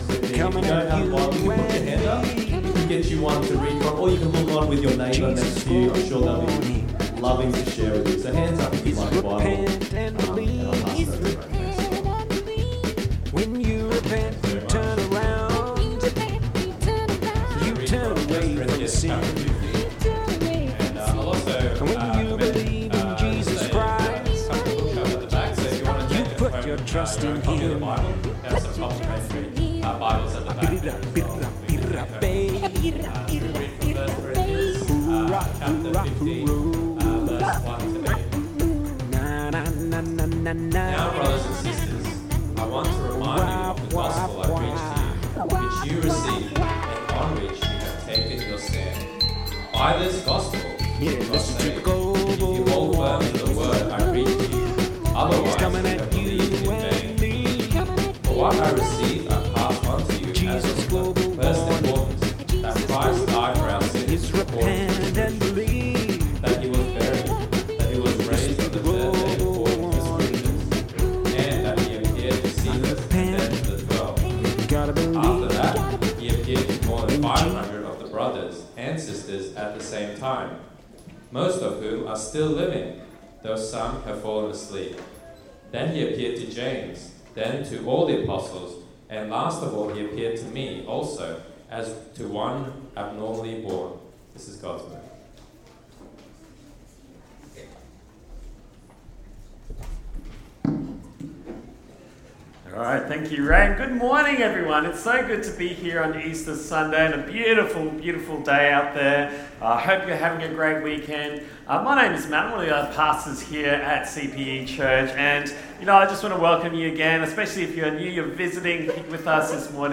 CPE Church - Easter Sunday @ CPE Church